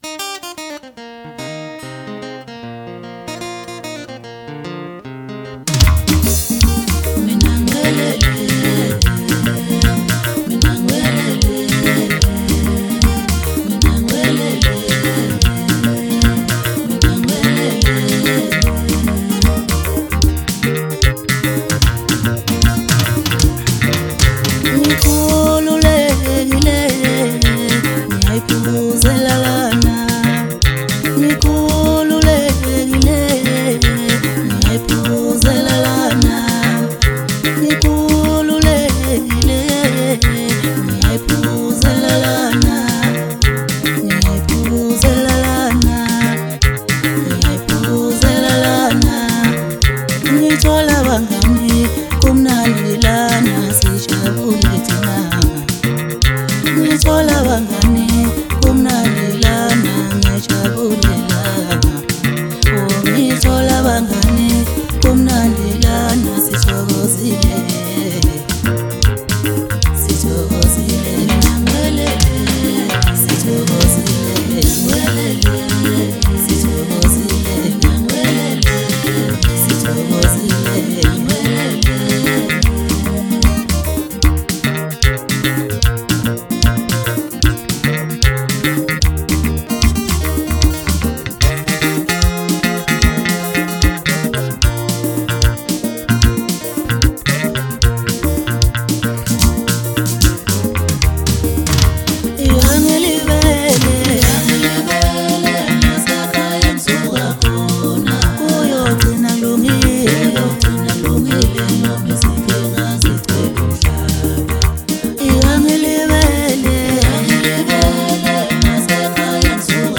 MASKANDI MUSIC
maskandi song